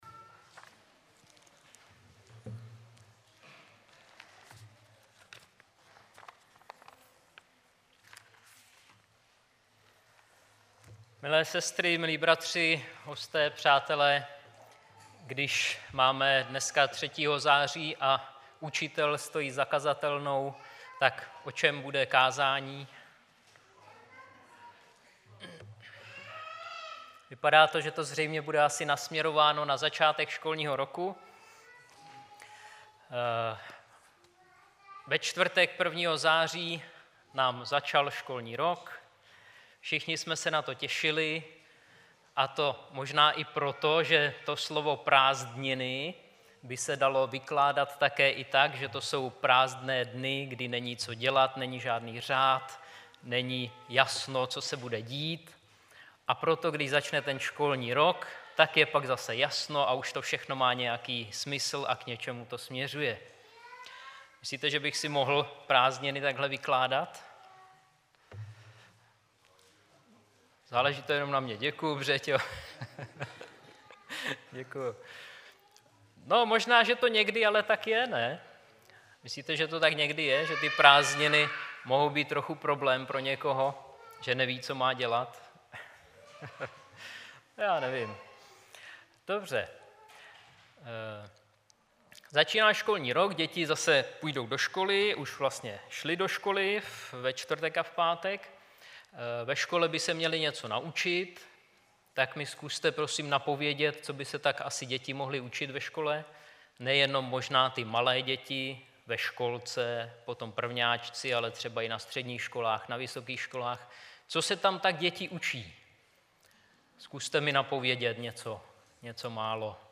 O milosrdenství O milosrdenství Sdílet článek! Twitter Digg Facebook Delicious StumbleUpon Google Bookmarks LinkedIn Yahoo Bookmarks Technorati Favorites Tento příspěvek napsal admin , 4.9.2016 v 10:33 do rubriky Kázání .